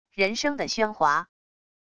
人声的喧哗wav音频